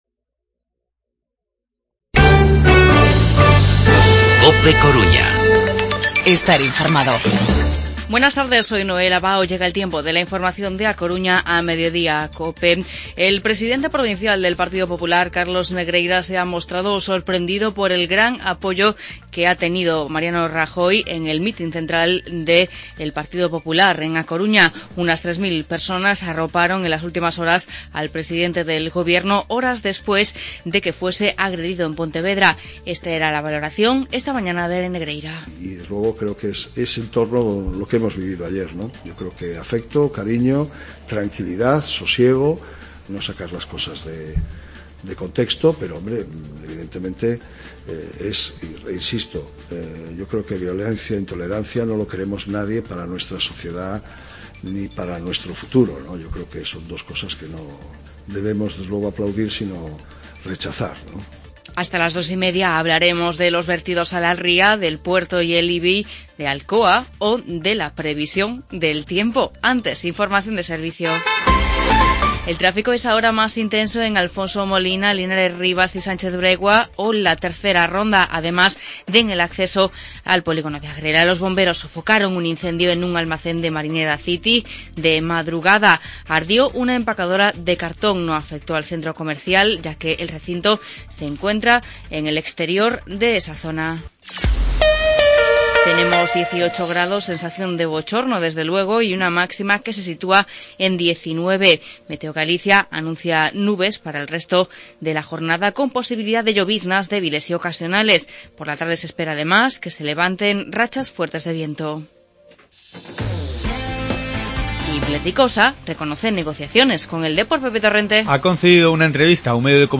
Informativo Mediodía COPE Coruña jueves, 17 de diciembre de 2015